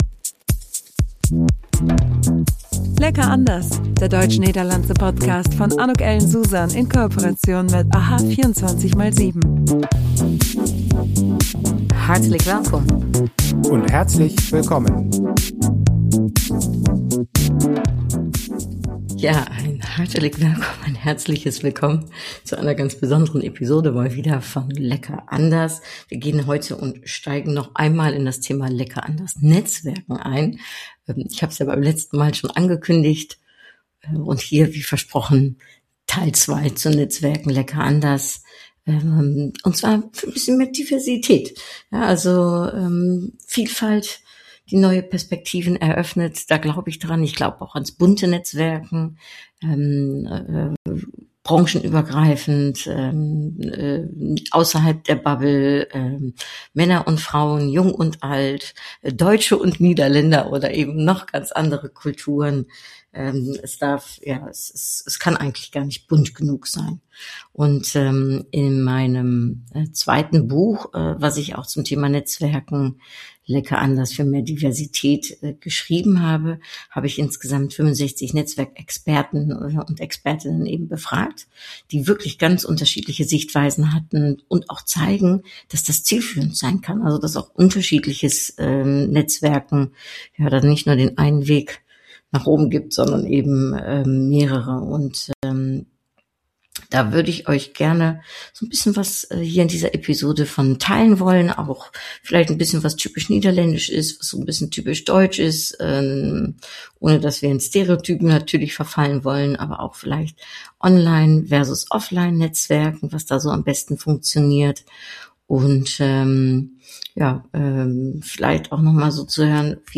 (DANKE NOCHMALS, das ist mir eine große Ehre und Freude) Ich lese daraus Auszüge vor.